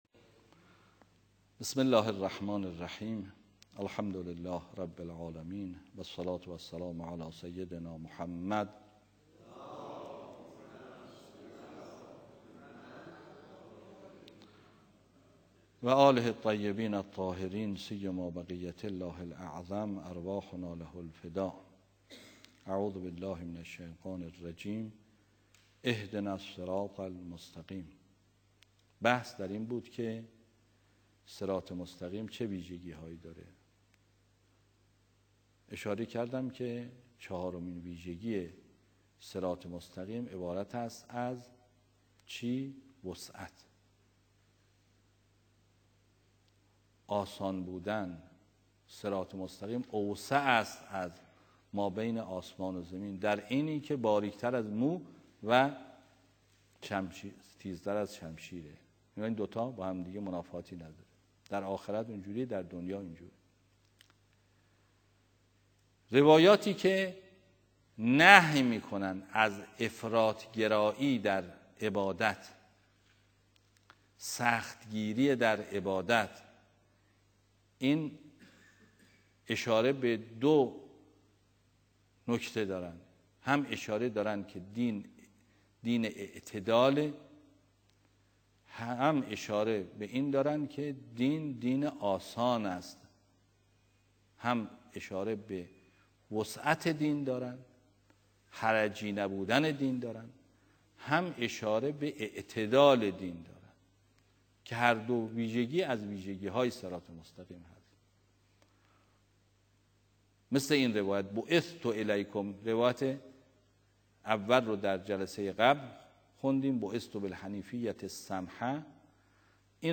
آیت‌الله ری‌شهری در جلسه تفسیر قرآن: